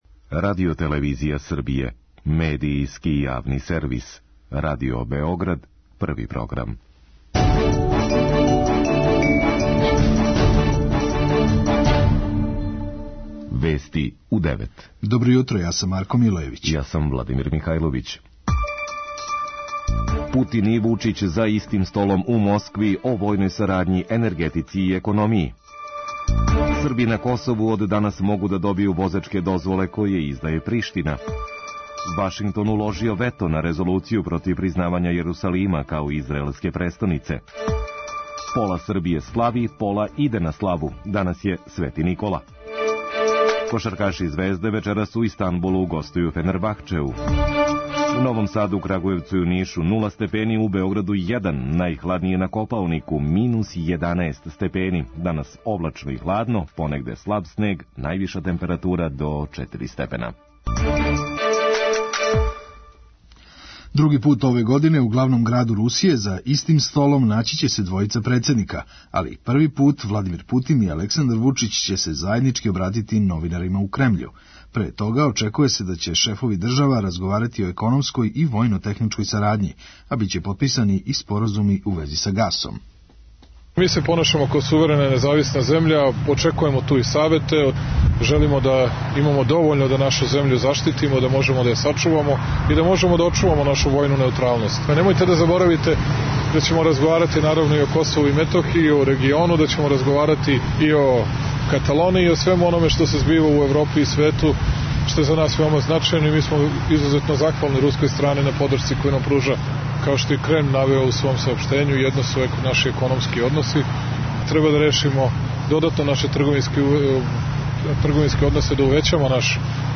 Вести у 9